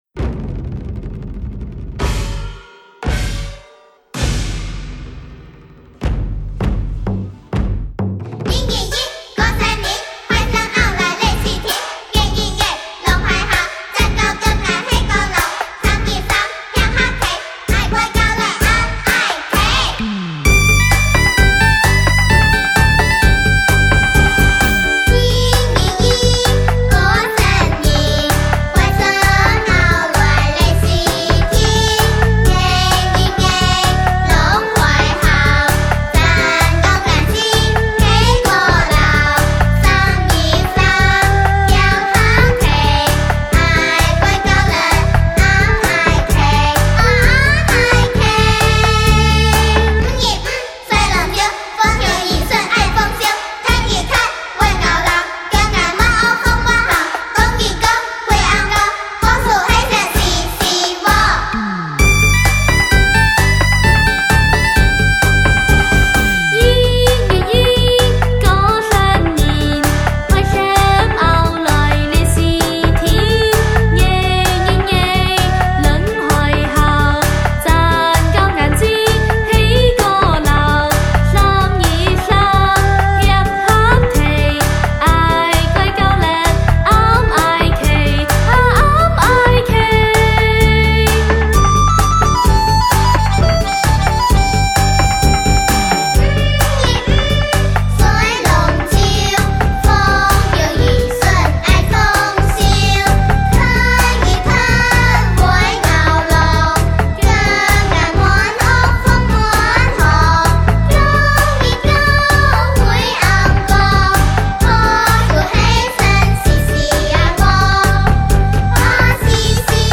开平民歌集